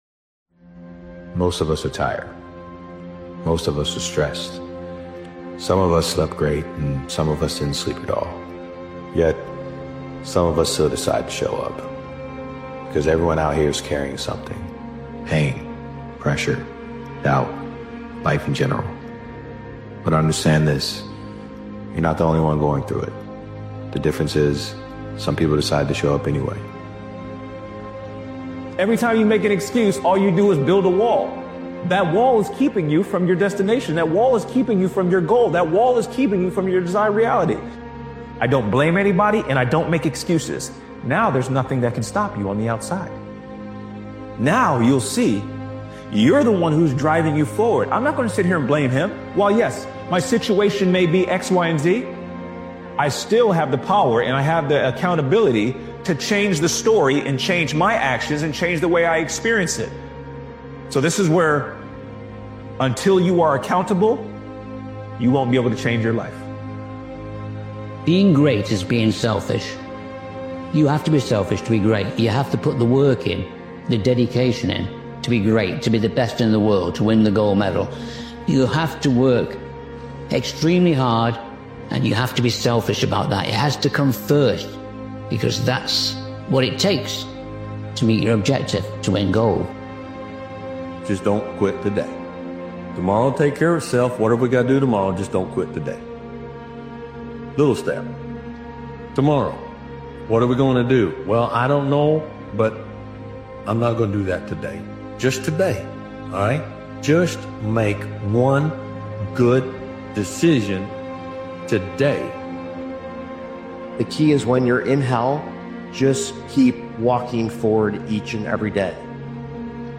This powerful motivational speech compilation is about refusing to follow the average path most people accept by default. Being the exception means holding higher standards, moving with intention, and staying committed when others fall off.